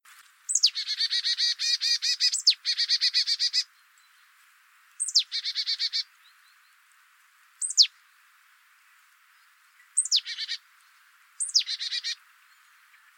Sumpfmeise Parus palustris Marsh Tit
Iller bei Rauns OA, 23.09.2012 14 s Rufe